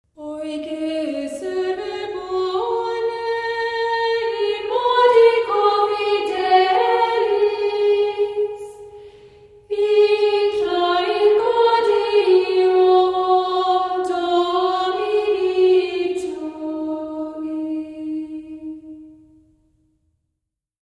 A must for early choral music enthusiasts!
Choral, Early music